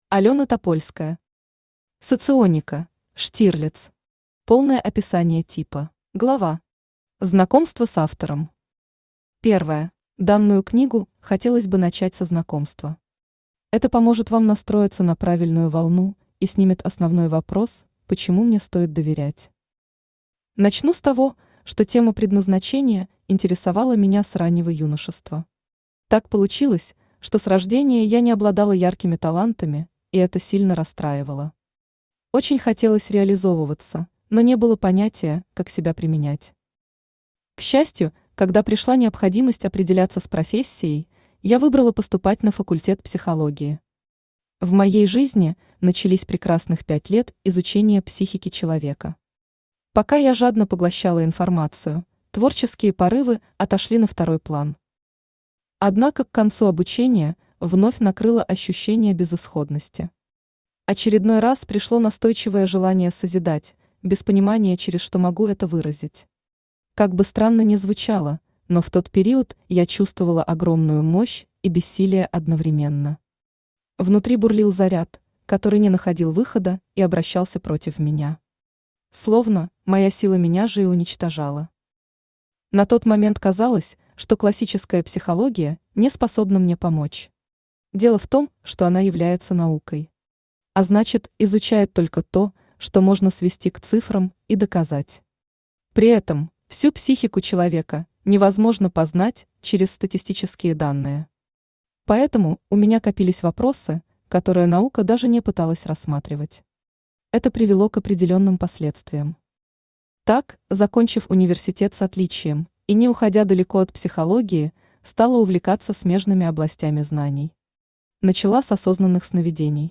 Аудиокнига Соционика: «Штирлиц». Полное описание типа | Библиотека аудиокниг
Читает аудиокнигу Авточтец ЛитРес.